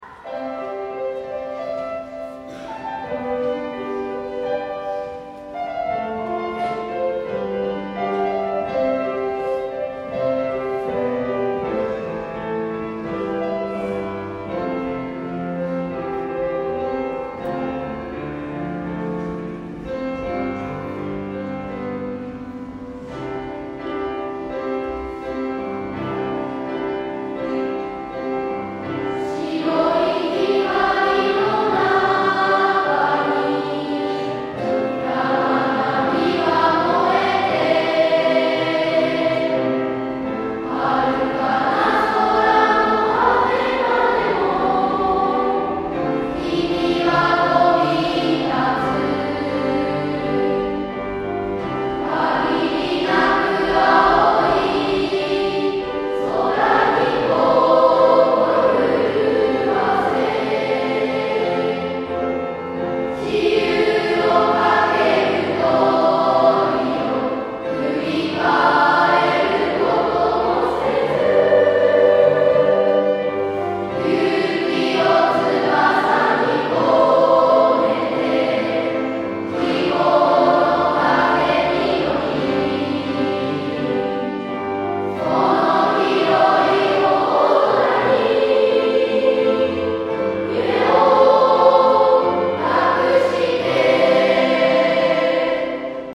花丸 卒業式その４
別れの言葉です。
大きな声で呼びかけや合唱ができました。